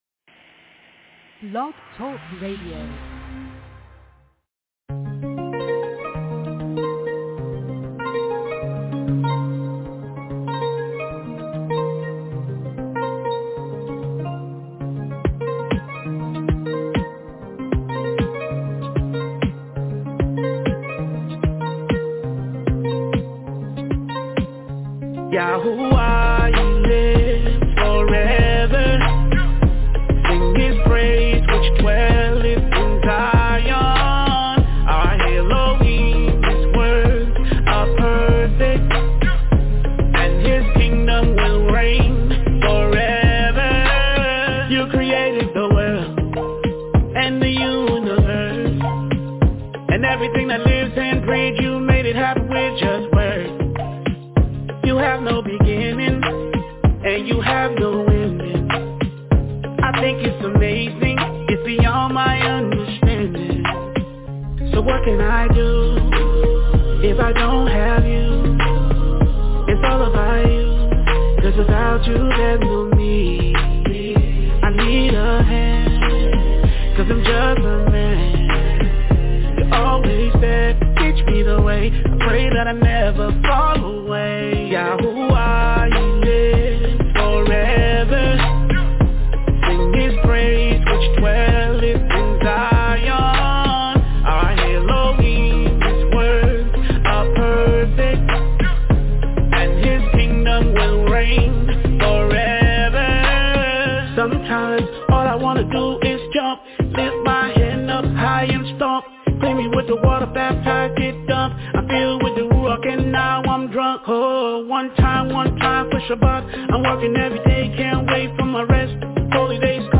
SATURDAY-MORNING-SABBATH-LESSON-PARABLES-OF-THE-KINGDOM-PART-2